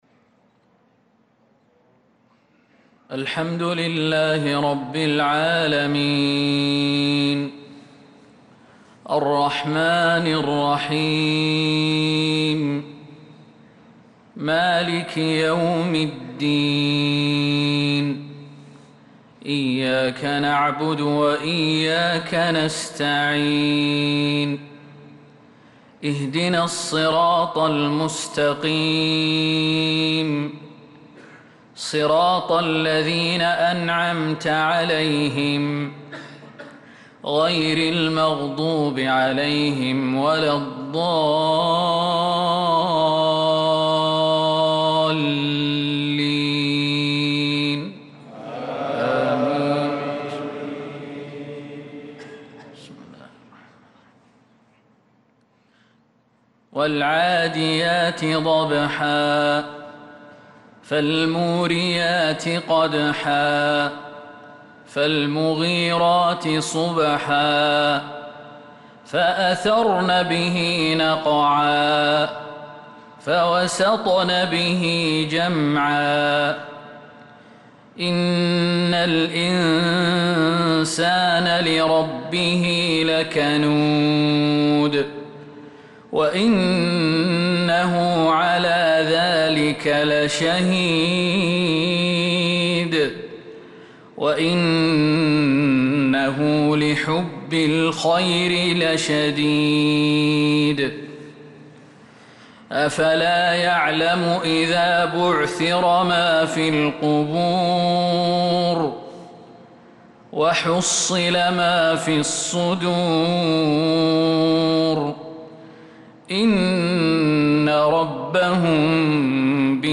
صلاة المغرب للقارئ خالد المهنا 14 ذو القعدة 1445 هـ
تِلَاوَات الْحَرَمَيْن .